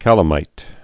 (kălə-mīt)